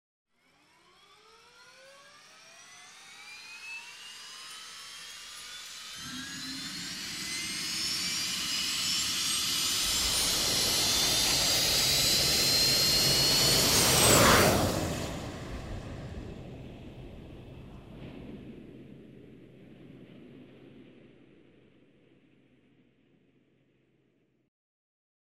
Звуки космоса
Рюкзак для полетов с реактивным запуском и быстрым перемещением